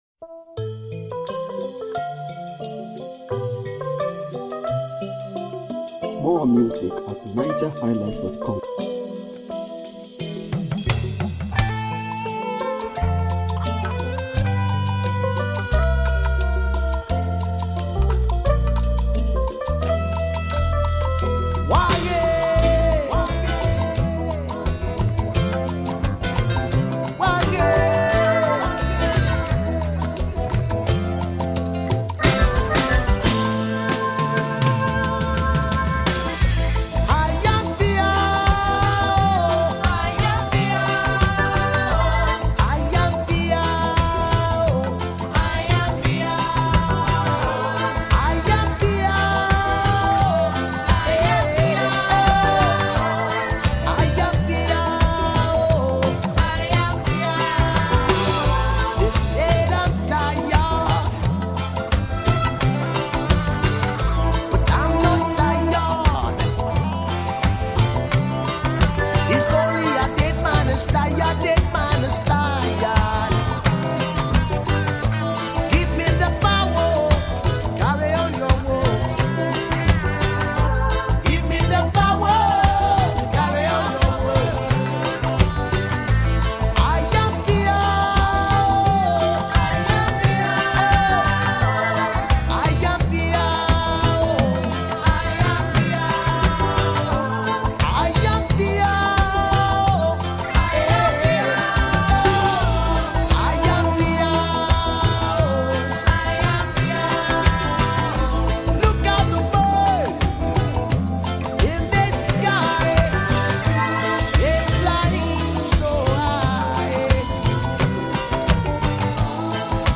Home » Ragae